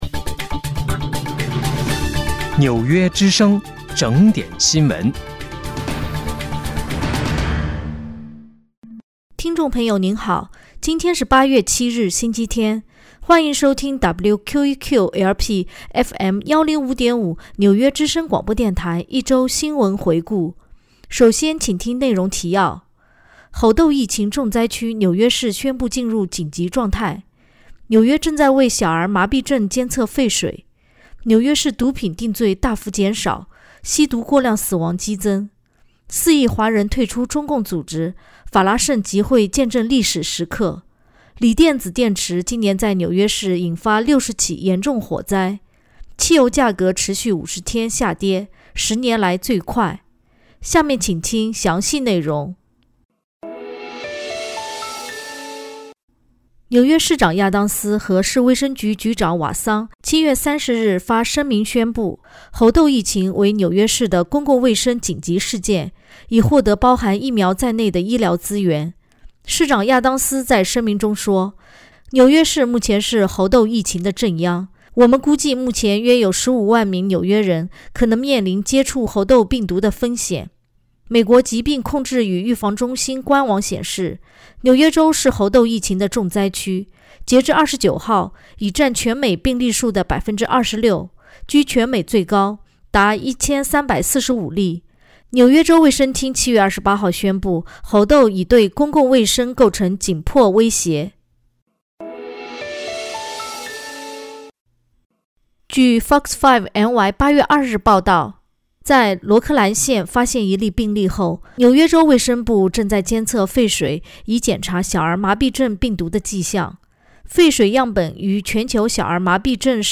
8月7日（星期日）一周新闻回顾